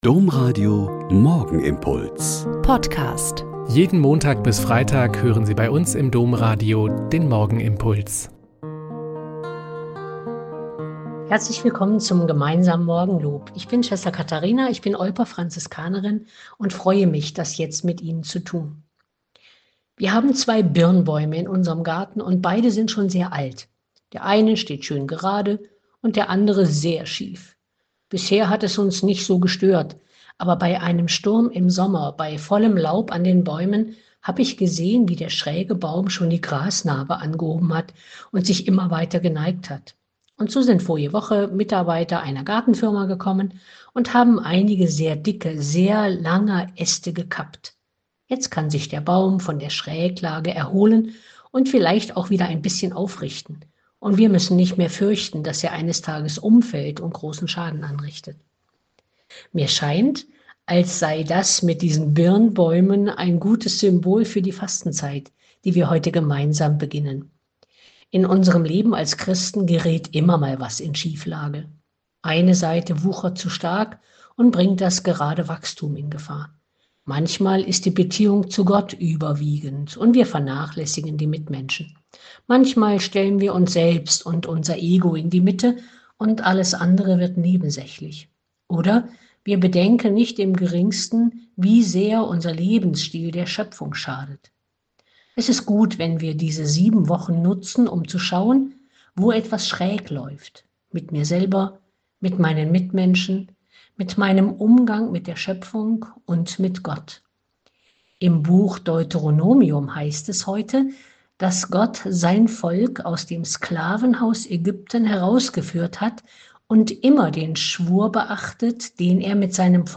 Lk 6,12-19 - Gespräch